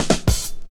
31DR.BREAK.wav